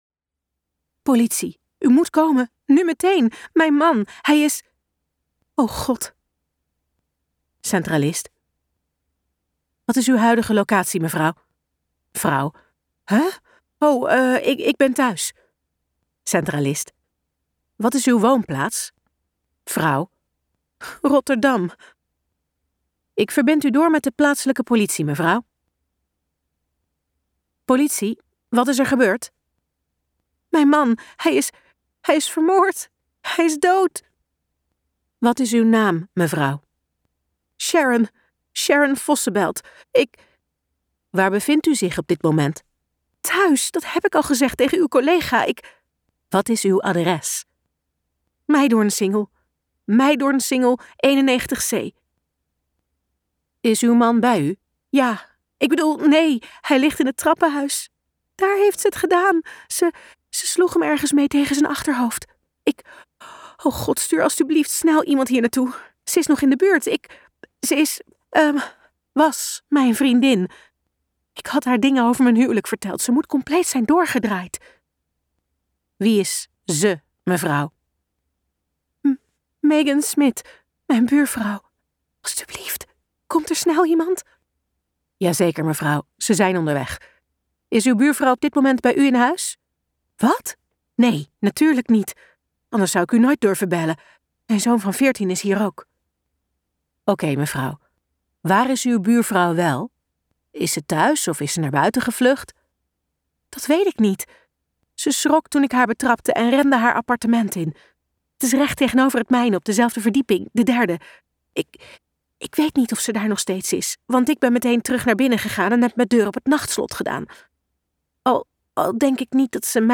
Ambo|Anthos uitgevers - Een aardig meisje luisterboek